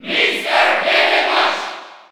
Category:Crowd cheers (SSB4) You cannot overwrite this file.
Mr._Game_&_Watch_Cheer_French_NTSC_SSB4.ogg